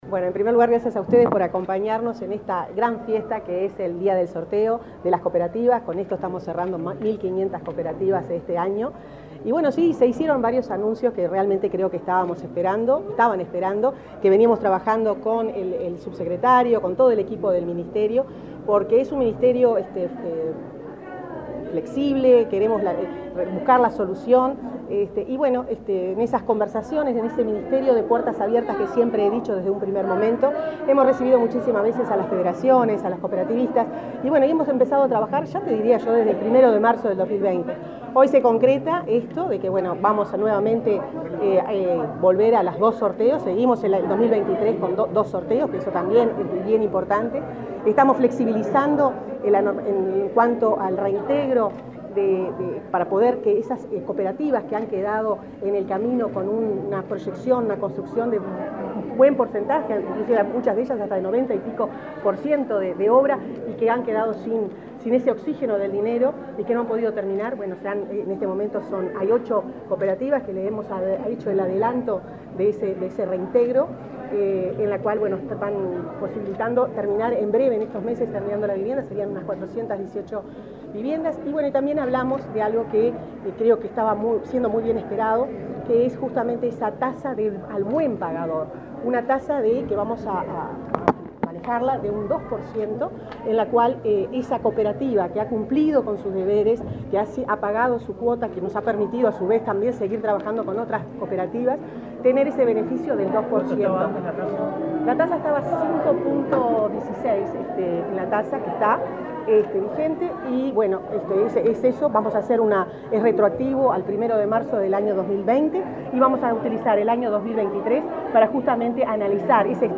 Declaraciones de la ministra de Vivienda, Irene Moreira
Declaraciones de la ministra de Vivienda, Irene Moreira 23/12/2022 Compartir Facebook X Copiar enlace WhatsApp LinkedIn La ministra de Vivienda, Irene Moreira, participó este viernes 23 en Montevideo en el segundo sorteo de 2022 de cupos para la construcción de viviendas cooperativas. Luego dialogó con la prensa.